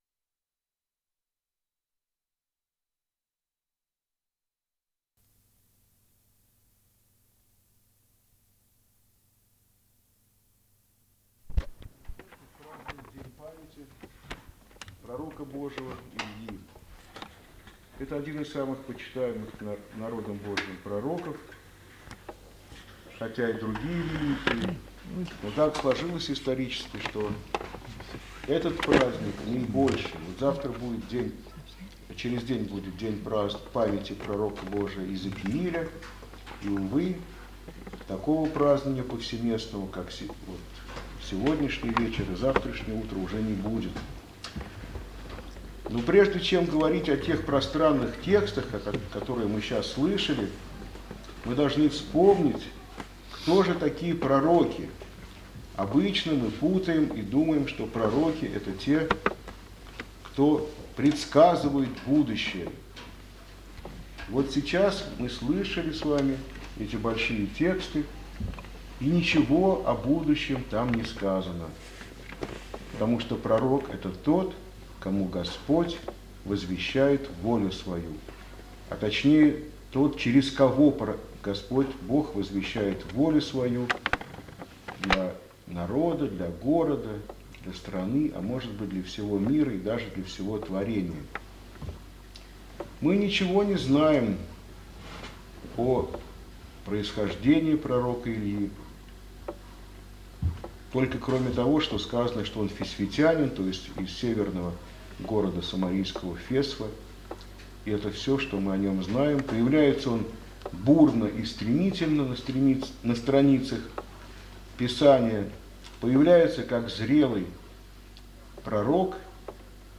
храм прп. Ефросинии Полоцкой г.Карсава